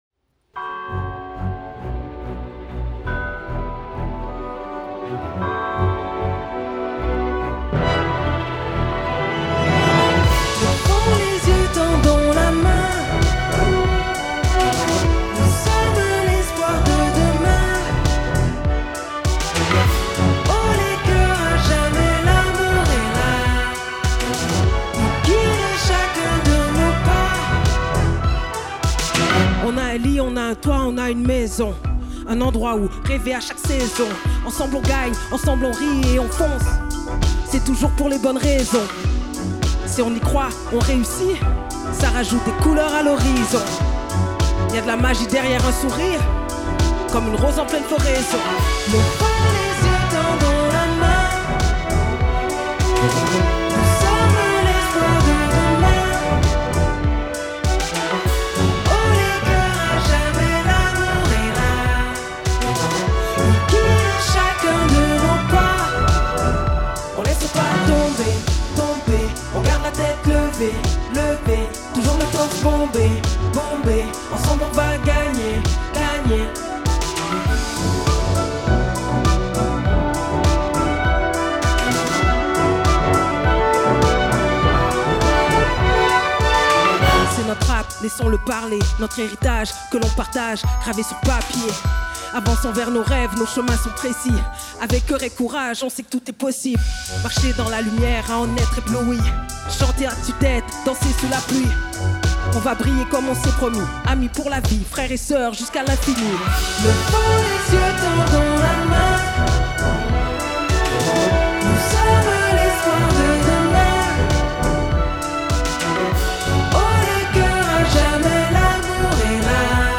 Version chantée